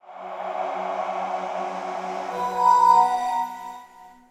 Samsung Galaxy S140 Startup.wav